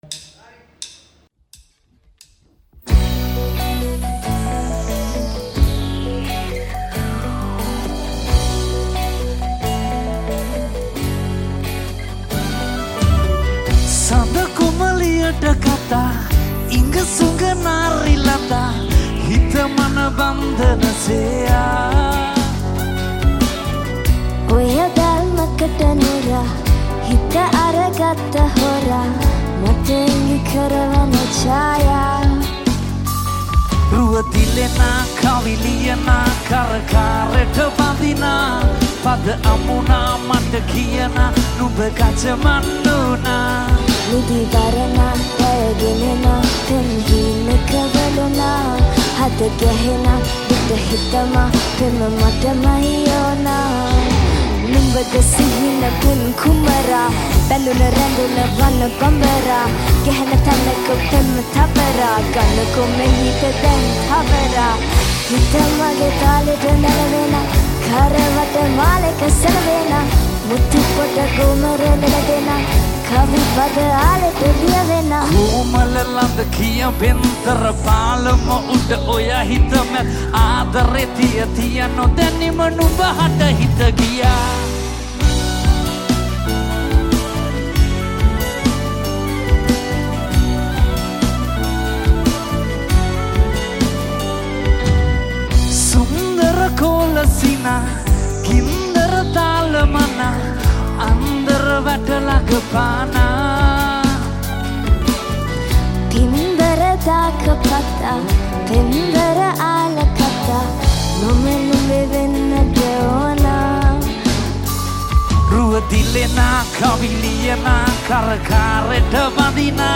Live Cover By